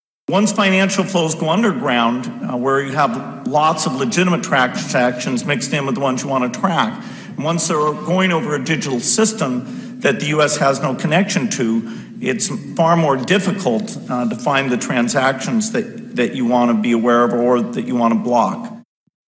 Click to hear Bill Gates speak of their